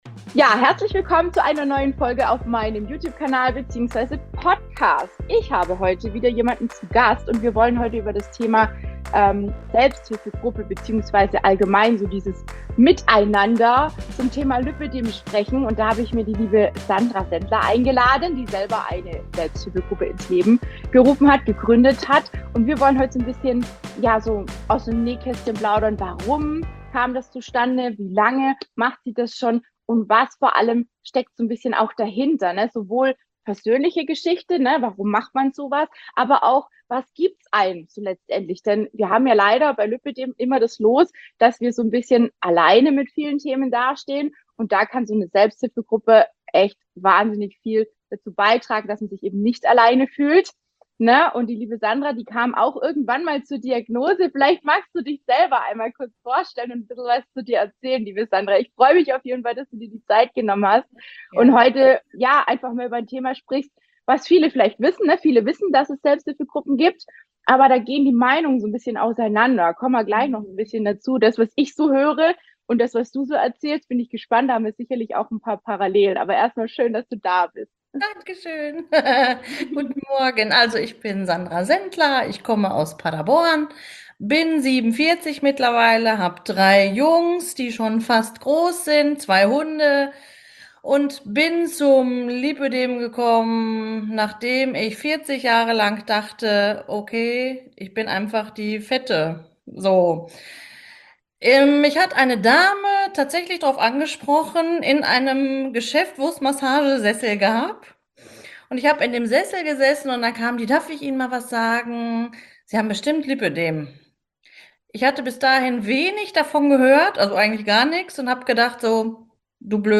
Im Verlauf des Interviews ist mir immer mehr klar geworden, dass nicht jede Frau so tolle Möglichkeiten des Austausches in der Nähe findet und umso mehr freu ich mich, dass ich durch mein Online-Konzept so viel mehr als Austausch und Unterstützung bieten darf.